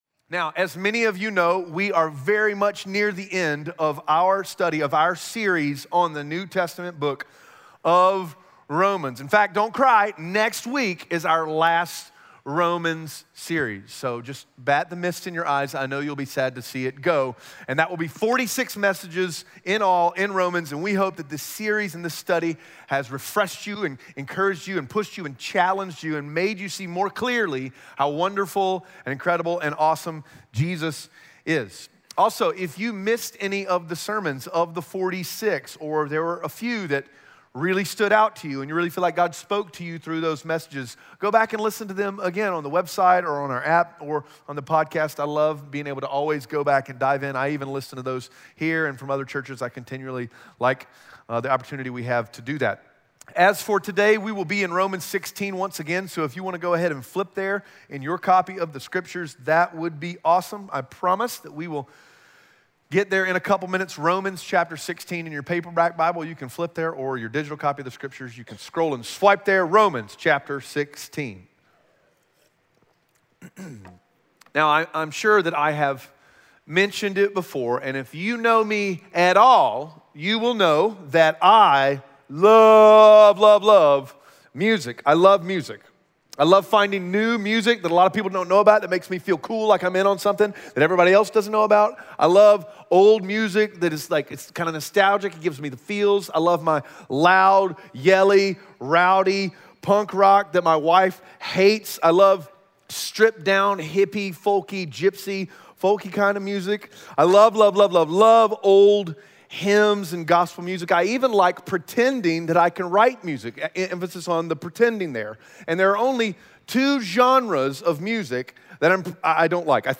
Romans 16:17-23 Audio Sermon Notes (PDF) Ask a Question Whatever you find beautiful, glorious, and good, you will fight for purity when it comes to that thing.